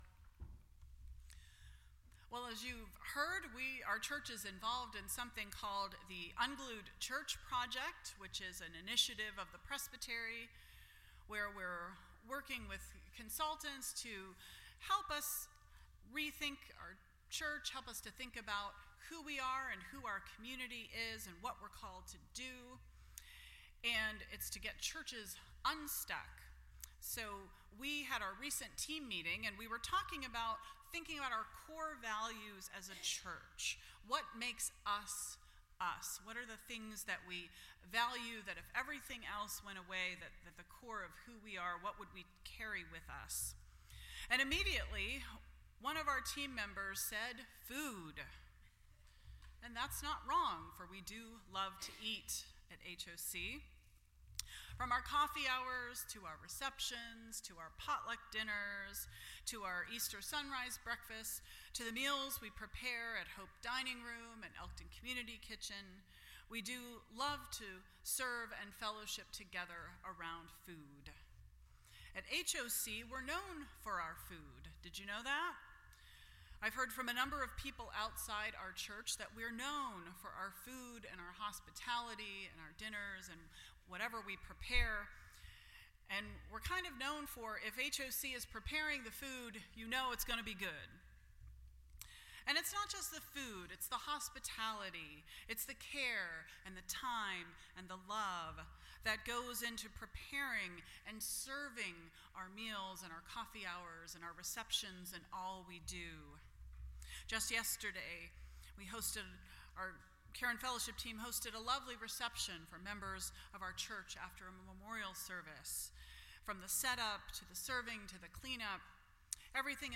The Lord's Prayer Service Type: World Communion Sunday %todo_render% Share This Story